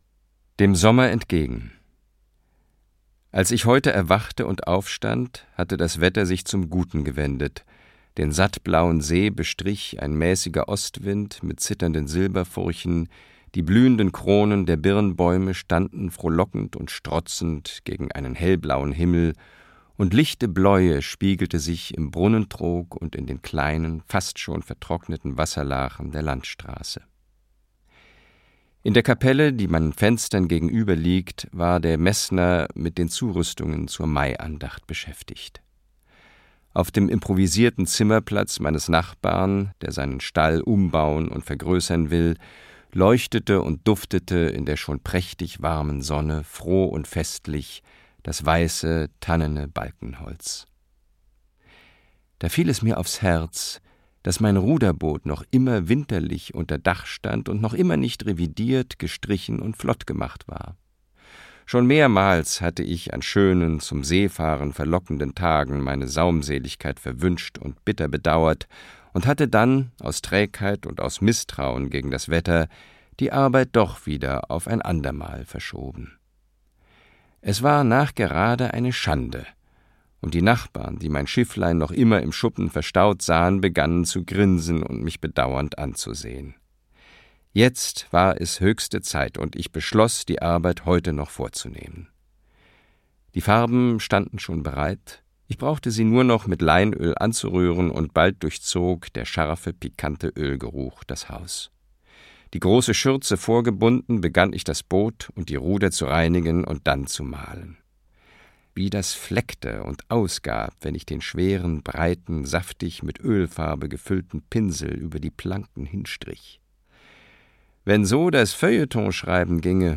Christian Berkel (Sprecher)
Neben der Schauspielerei hat Berkel sich als hervorragender Sprecher etabliert.